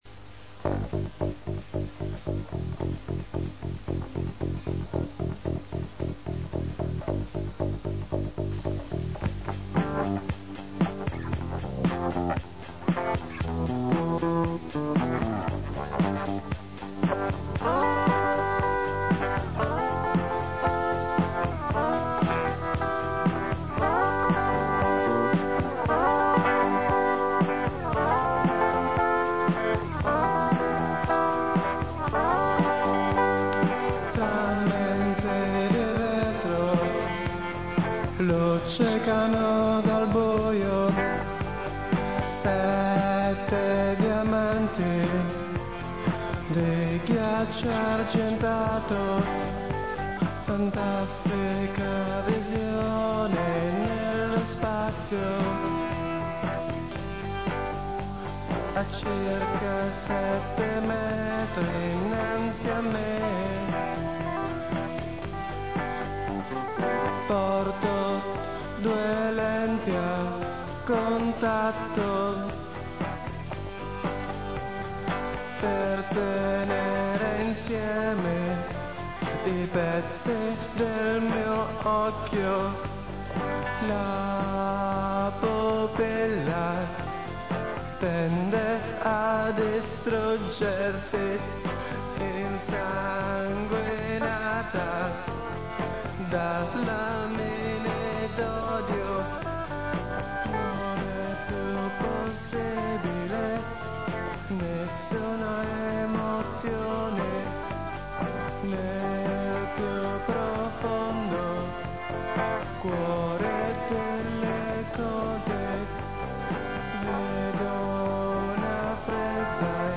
voce, chitarra
chitarra, tastiere
basso
batteria elettronica
Registrato al Push-Pull studio di Empoli nel 1984